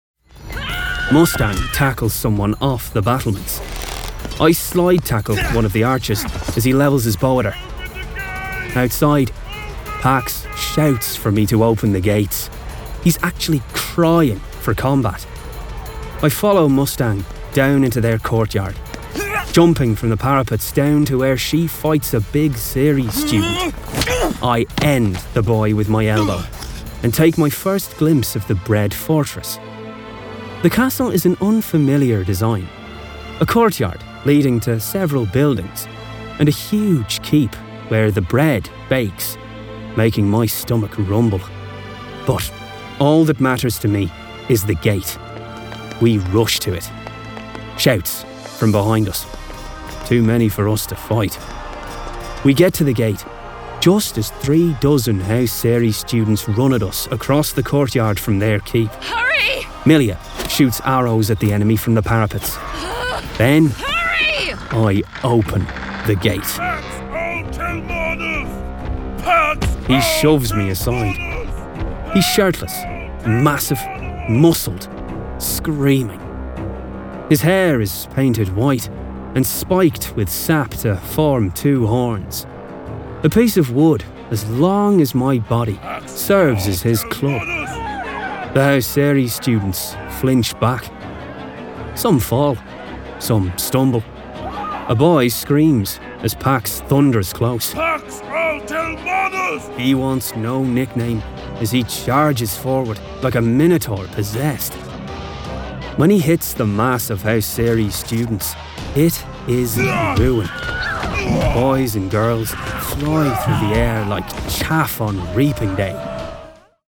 Full Cast. Cinematic Music. Sound Effects.
Adapted from the novel and produced with a full cast of actors, immersive sound effects and cinematic music!